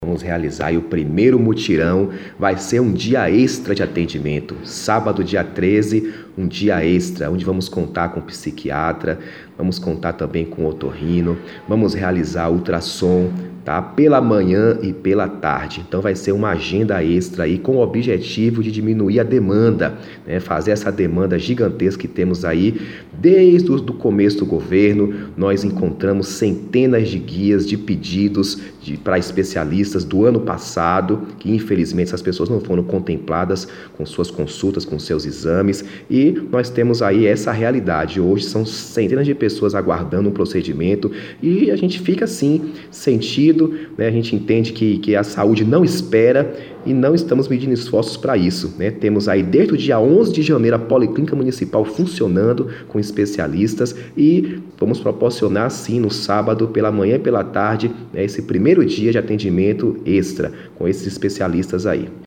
Acesse o Podcast e ouça as explicações do Secretário de Saúde, Darkson Marques.